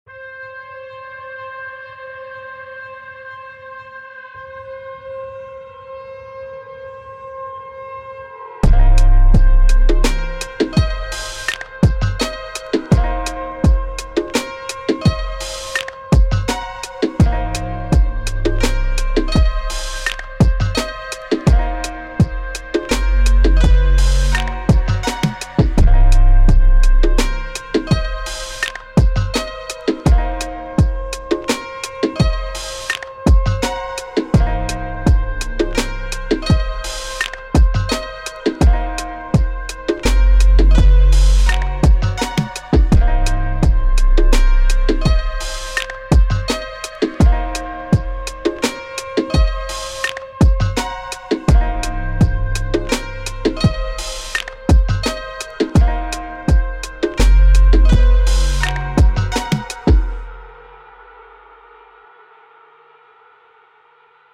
quick and dirty little sketch